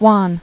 [wan]